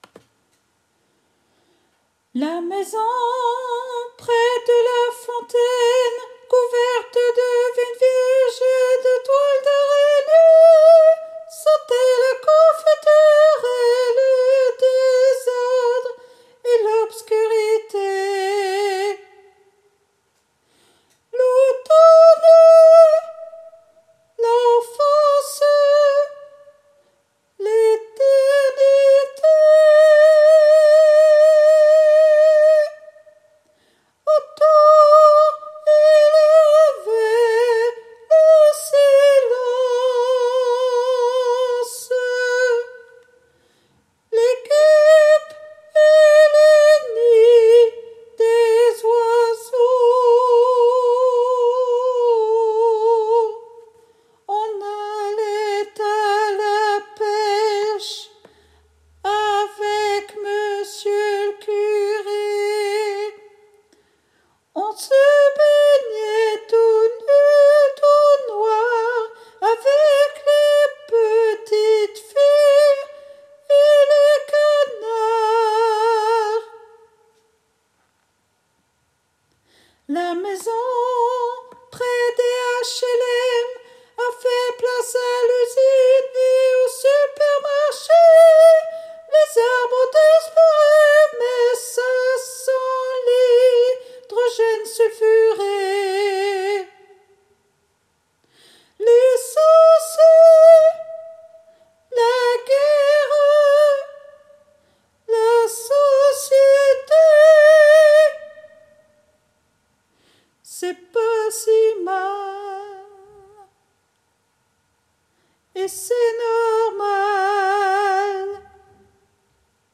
MP3 versions chantées
Ténor